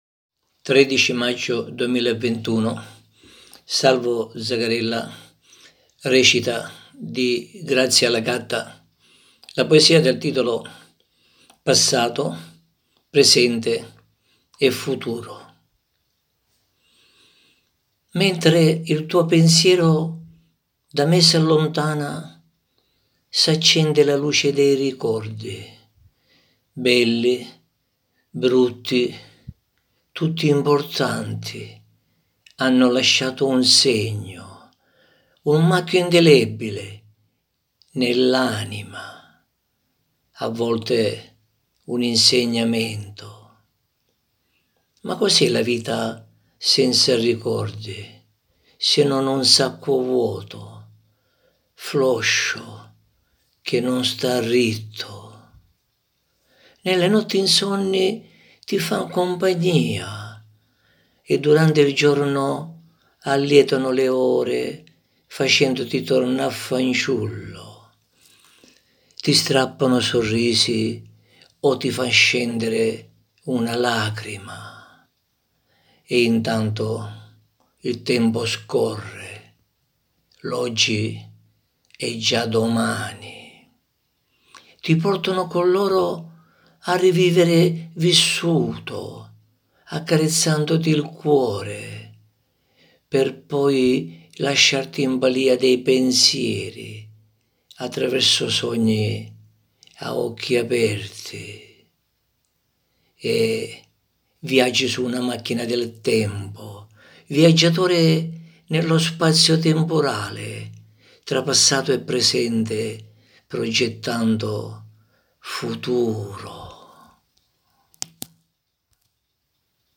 interpreta la poesia